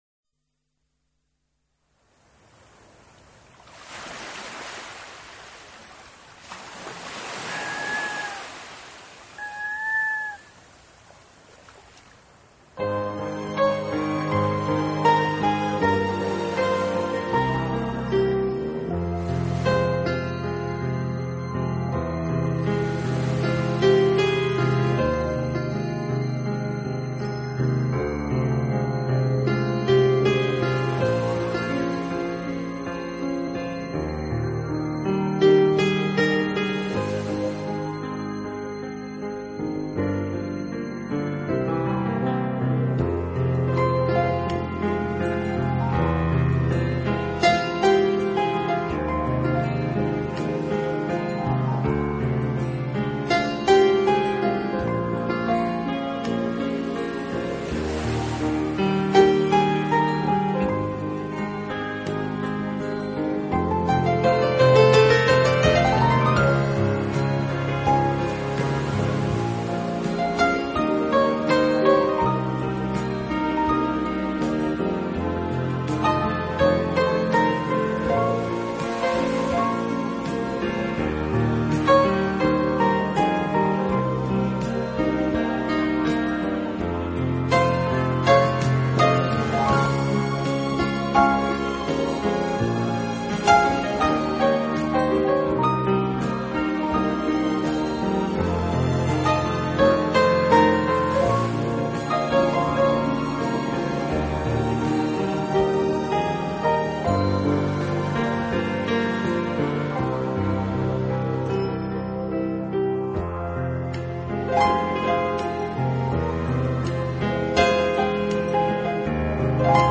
音乐流派： New Age/CLassical